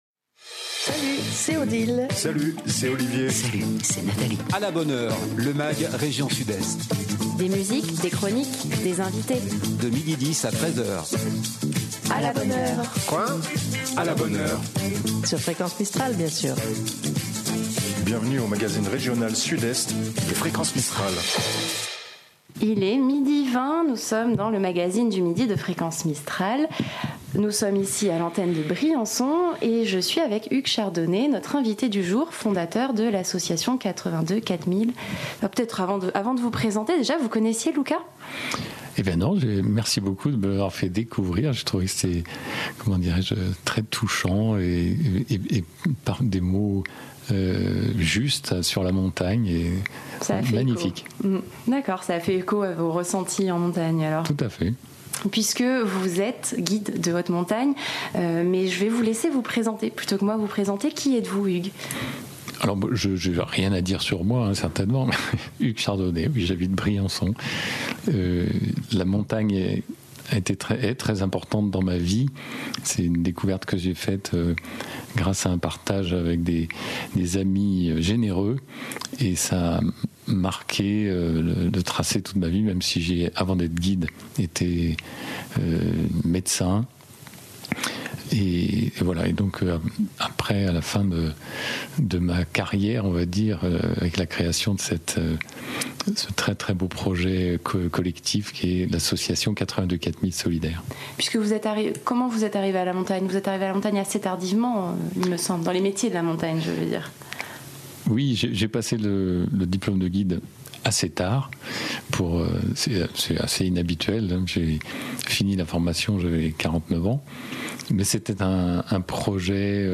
Sur le plateau, en direct